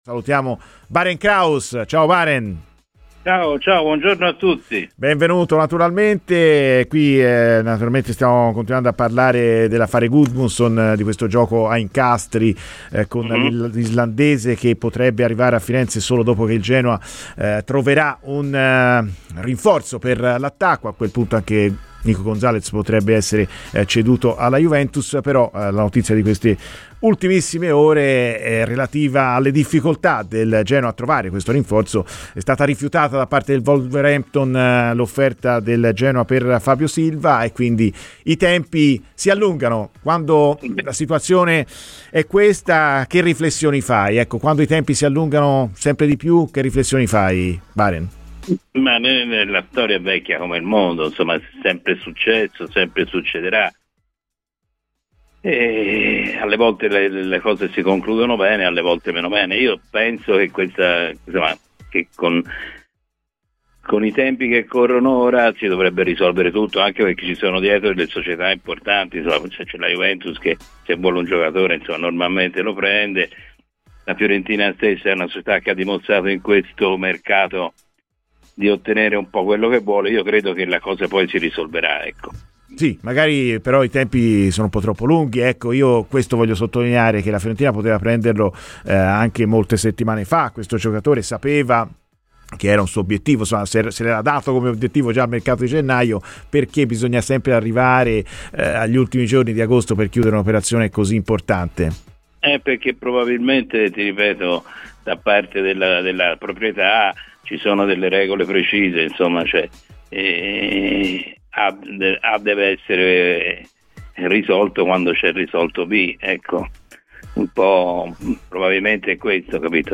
Ascolta il podcast per l'intervista integrale.